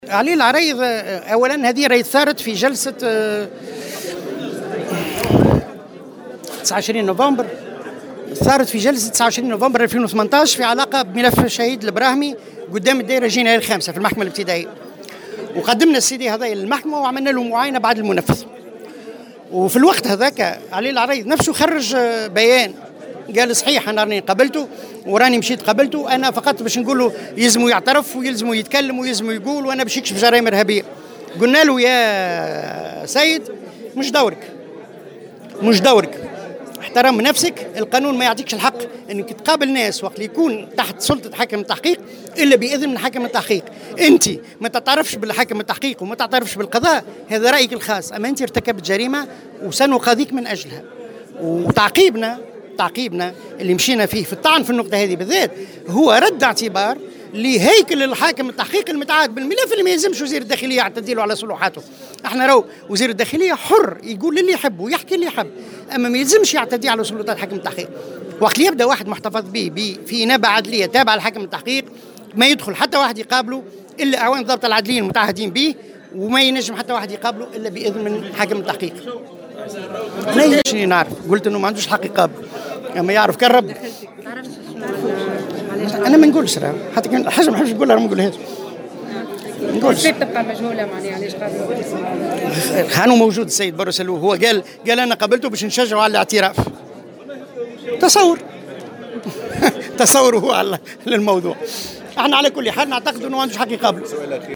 على هامش ندوة صحفية بالحمامات